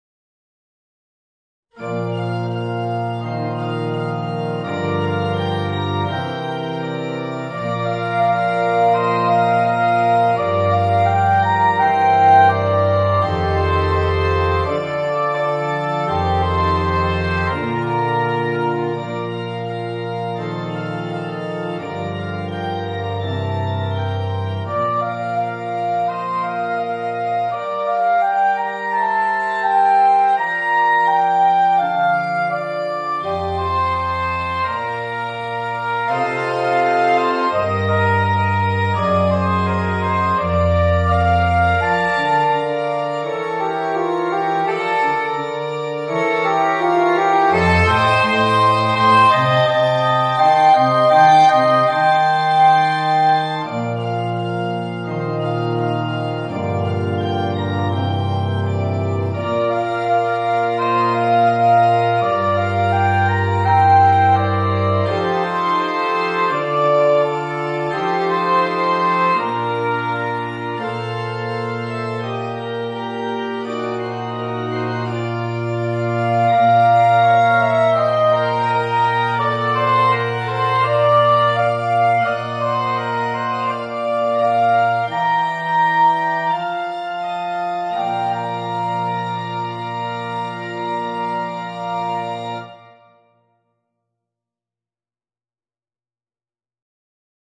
für Sopransaxophon und Klavier oder Orgel